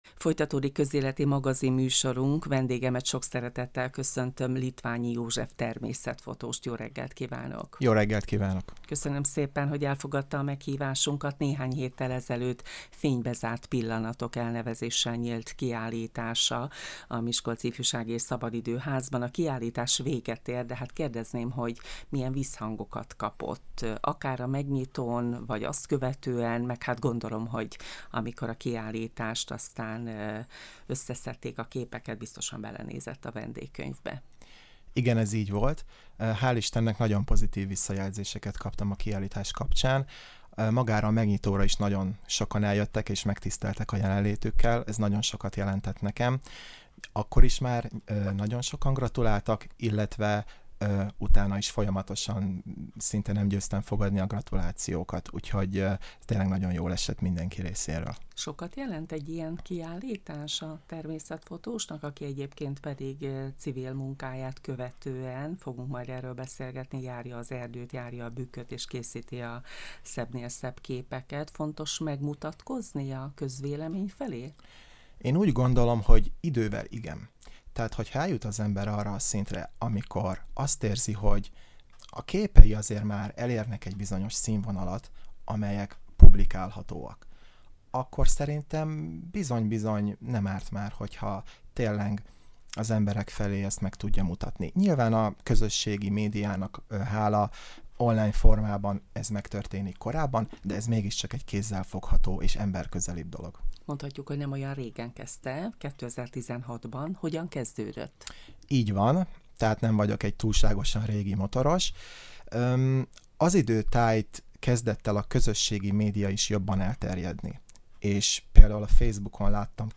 „Fénybe zárt pillanatok” - Beszélgetés